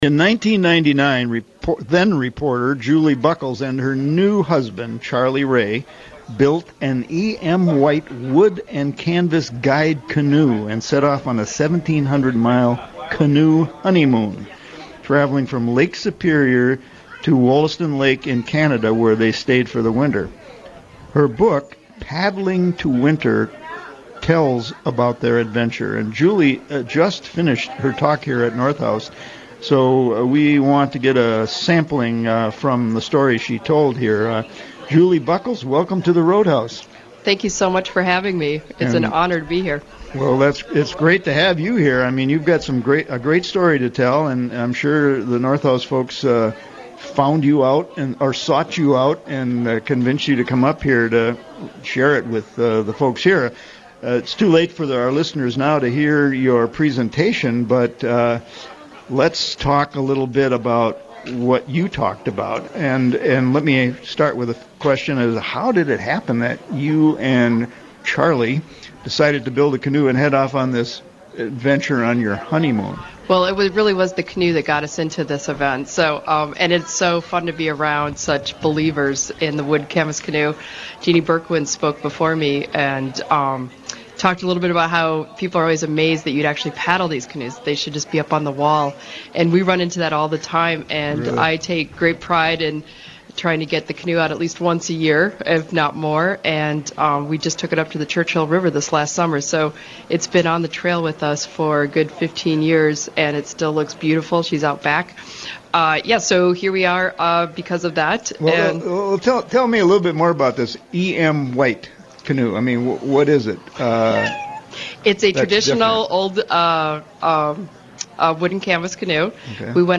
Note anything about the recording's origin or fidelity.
live at the North House Wooden Boat Show on the harbor. Program: The Roadhouse